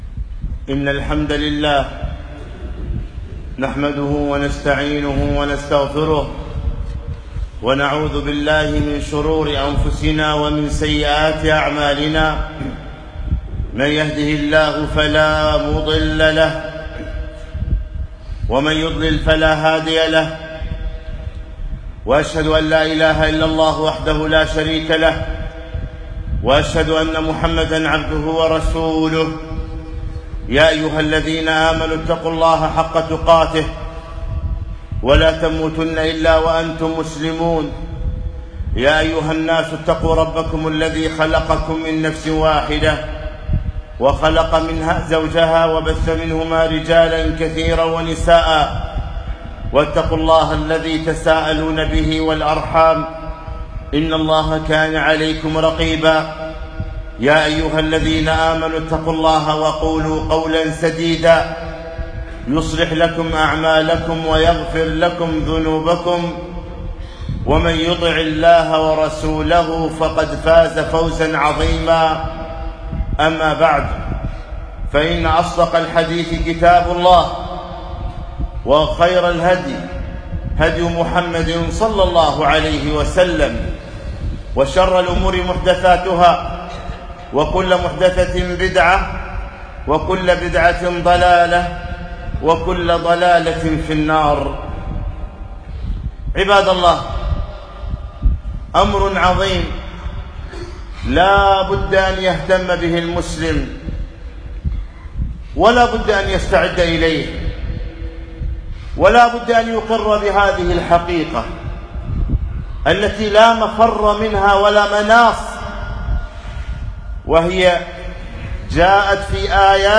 خطبة - فوربك لنسألنهم أجمعين